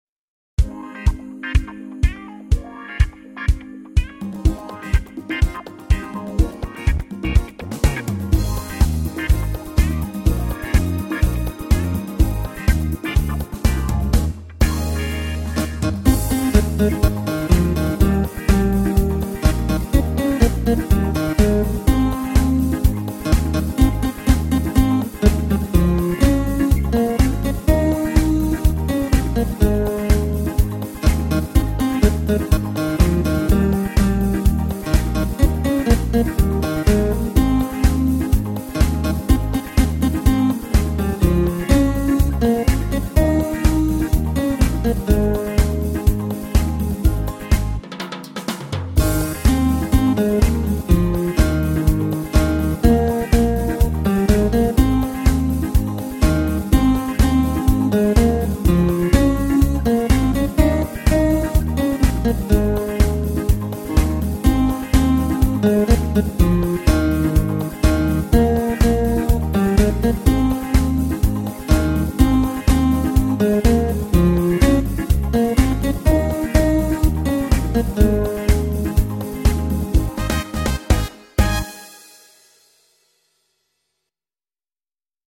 I heard this song in Zamboanga